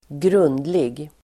Uttal: [²gr'un:dlig]